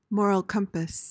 PRONUNCIATION:
(MOR-uhl kuhm-puhs)